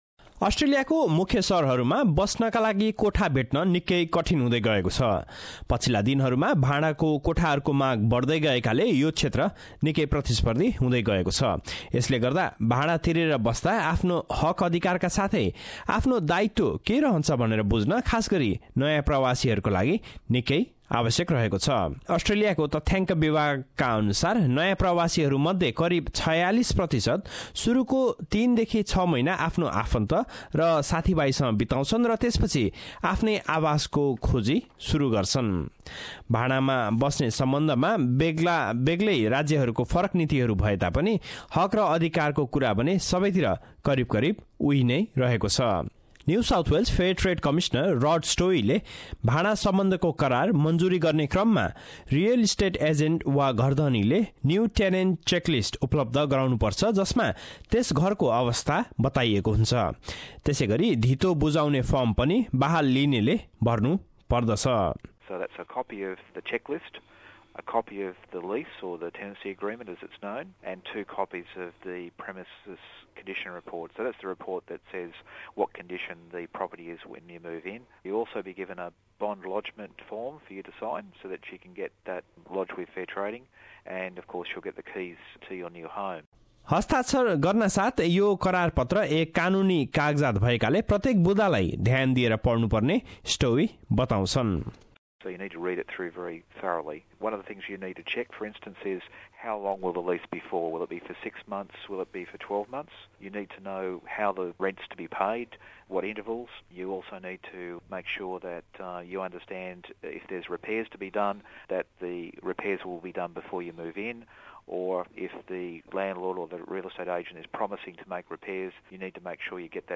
अस्ट्रेलियामा घर वा अपार्टमेन्ट भाँडामा लिंदा तपाईंको अधिकार र जिम्मेवारी बारे जानकारी लिनु धेरै जरुरी रहेको छ। यसबारे एक रिपोर्ट।